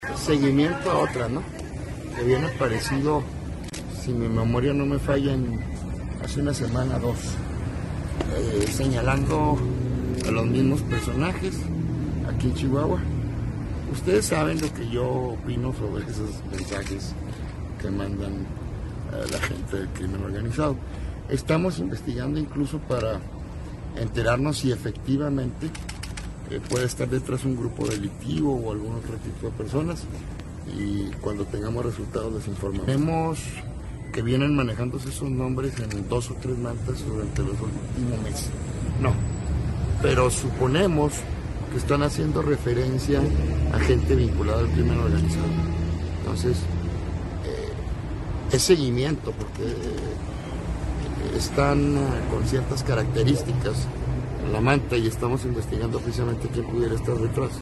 AUDIO: CÉSAR JÁUREGI MORENO TITULAR DE LA FICALÍA GENERAL DEL ESTADO (FGE)